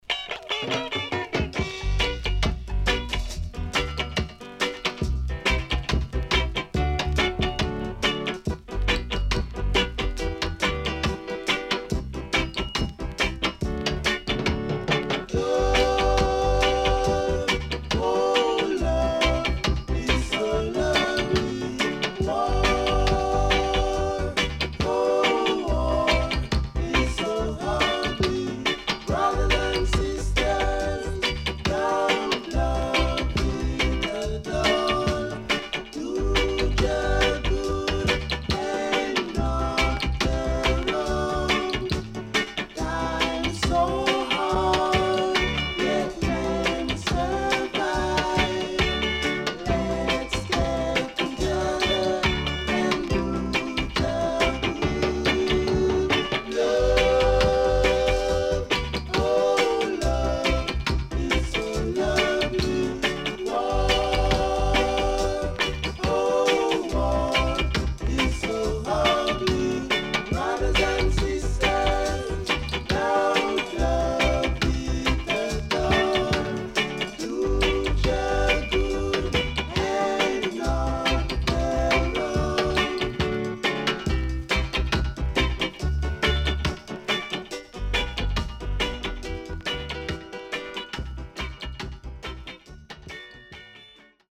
Back Vocal